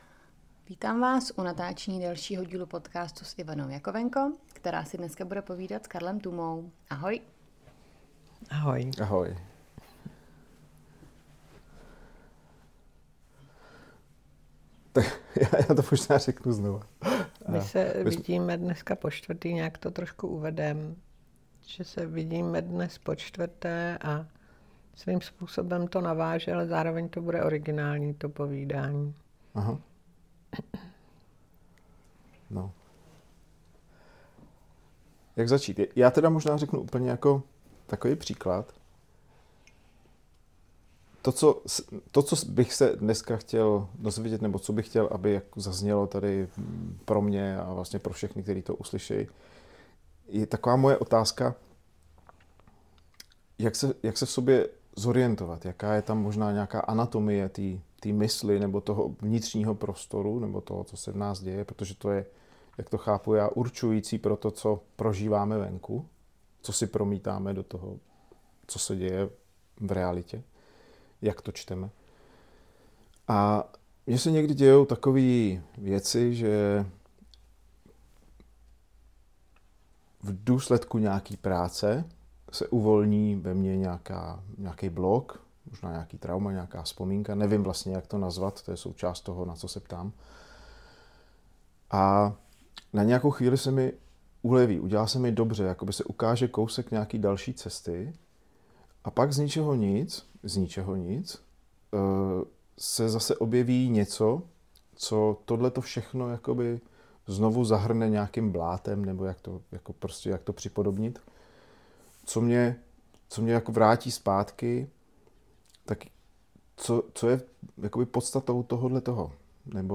Takové povídání o životě a třeba o vnitřním a i vnějším světě. Tentokrát je to o tom vnitřním světě.